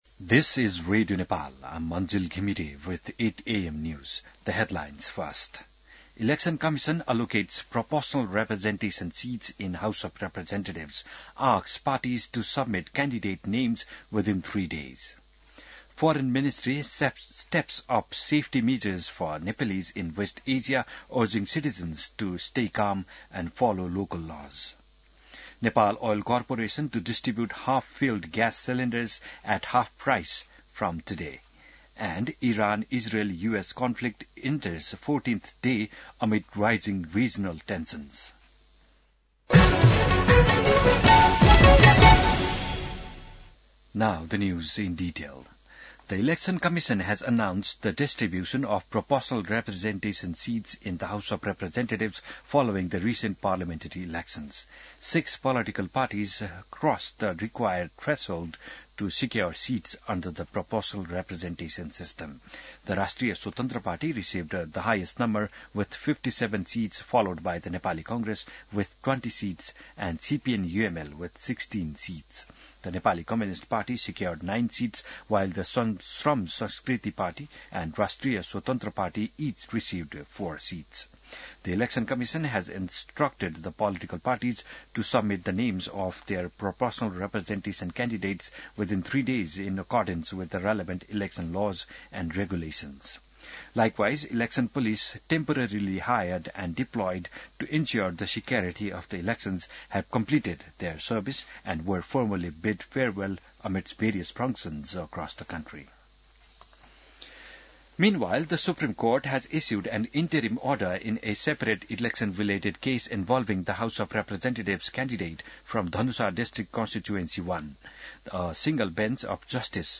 An online outlet of Nepal's national radio broadcaster
बिहान ८ बजेको अङ्ग्रेजी समाचार : २९ फागुन , २०८२